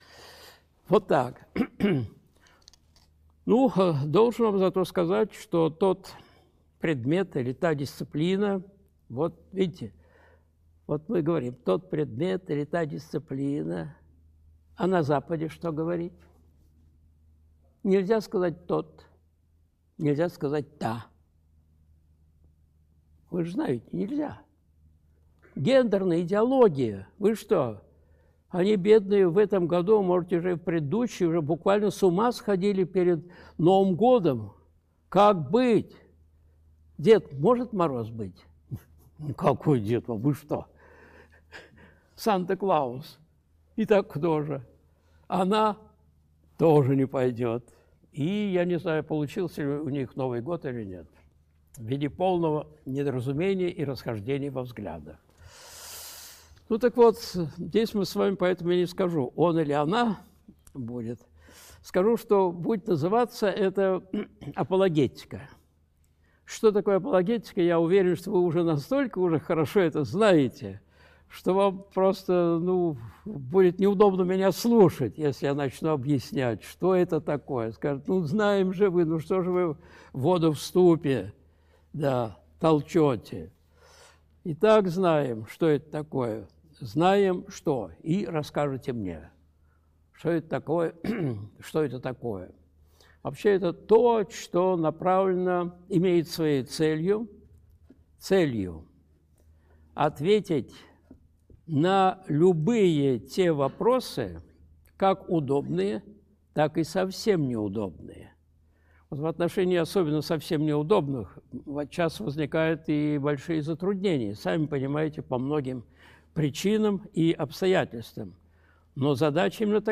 Зачем мы учимся в духовной школе? (Апологетика, лекция 1. МДА, 27.01.2023)
Видеолекции протоиерея Алексея Осипова